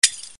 Vetro che si rompe
Suono corto secco di vetro che si rompe.